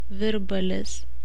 Virbalis.ogg.mp3